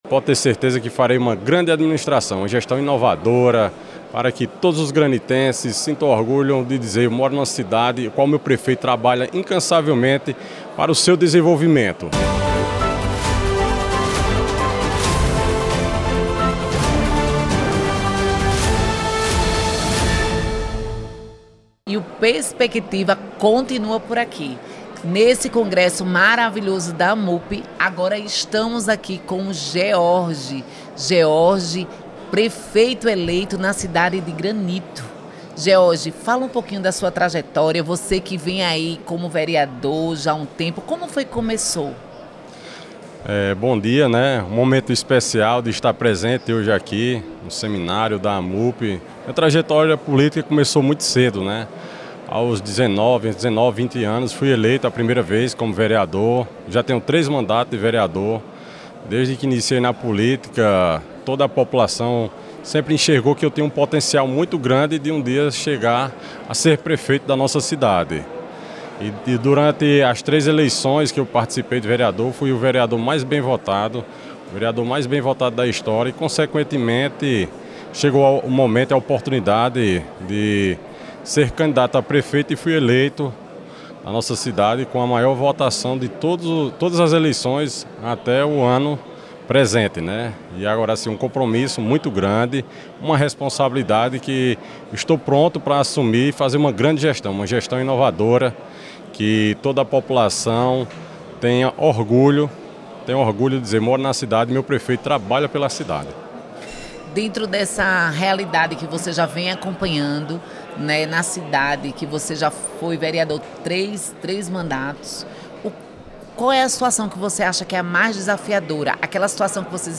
Em entrevista ao programa Perspectiva, da Rede Você, George reforçou sua determinação em deixar um legado de transformação.